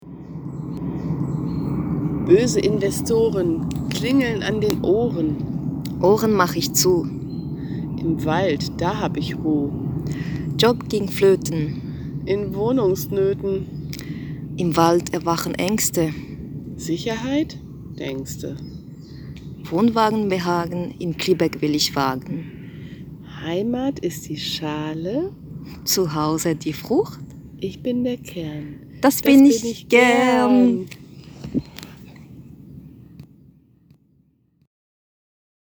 Die Hörbeiträge aus dem Tram
Gedicht